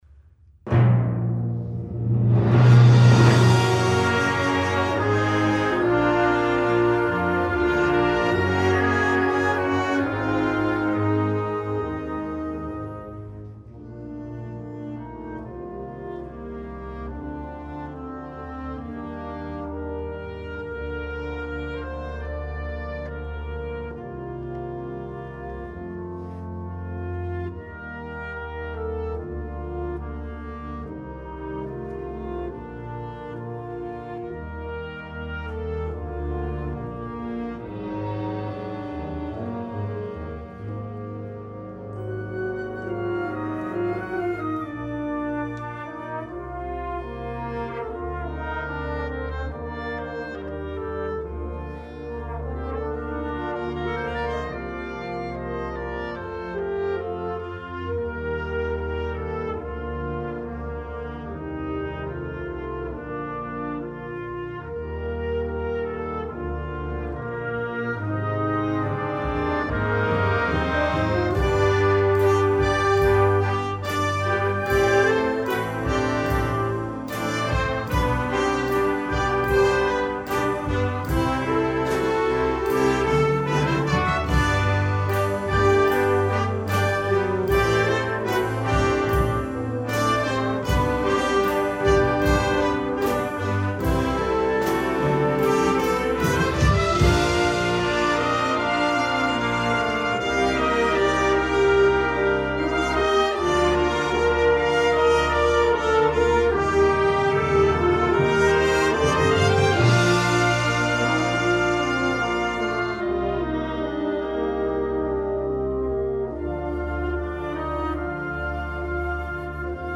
Gattung: Konzertwerk
5:00 Minuten Besetzung: Blasorchester PDF